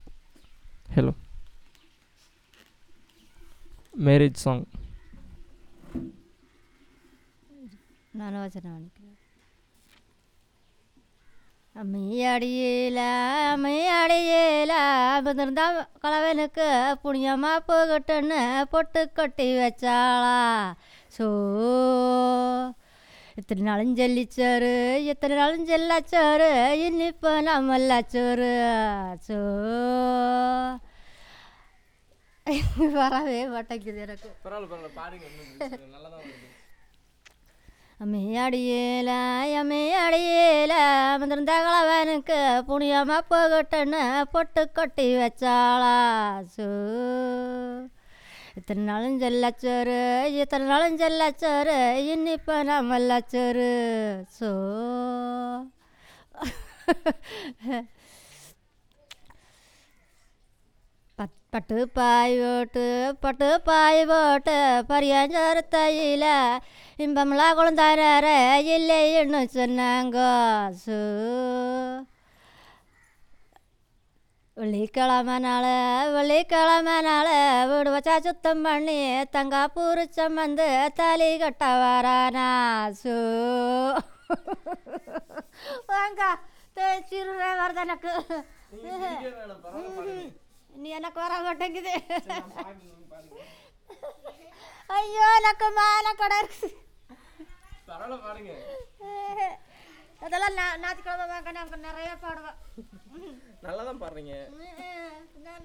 Performance of marriage song